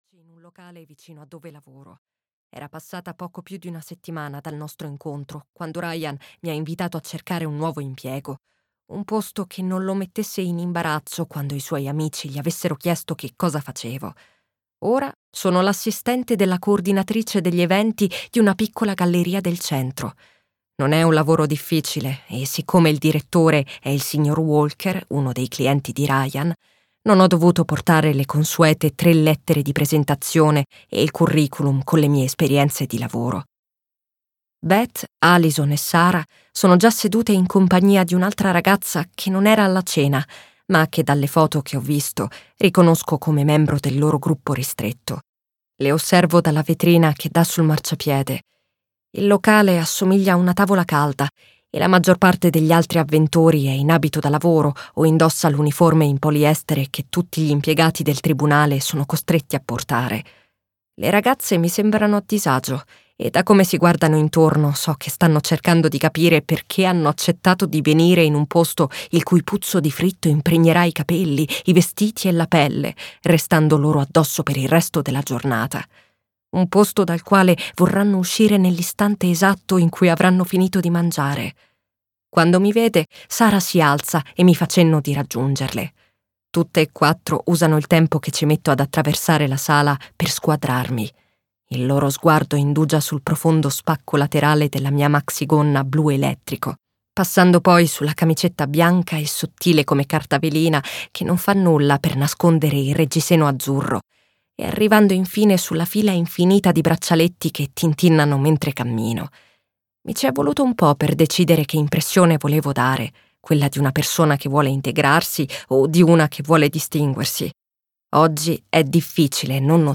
"La prima bugia vince" di Ashley Elston - Audiolibro digitale - AUDIOLIBRI LIQUIDI - Il Libraio